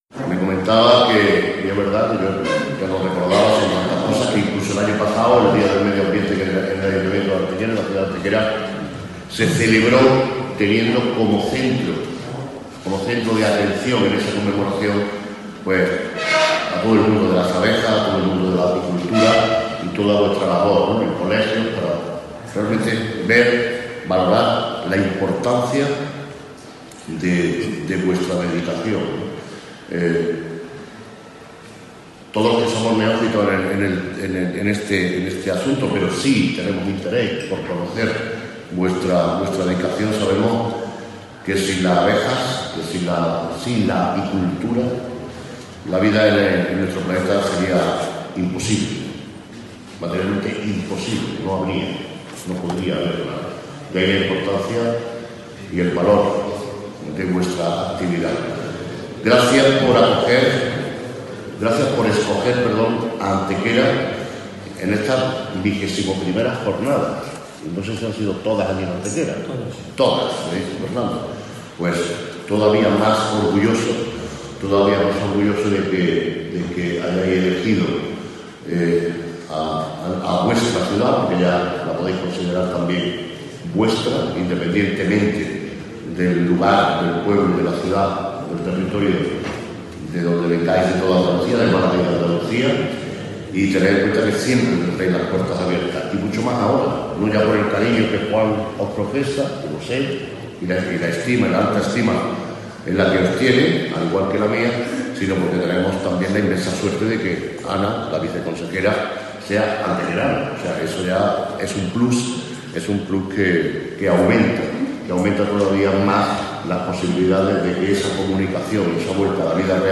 El Alcalde de Antequera inaugura la vigésimo primera edición de la Jornada Malagueña de Apicultura celebrada el pasado sábado en Antequera
Cortes de voz M. Barón 849.64 kb Formato: mp3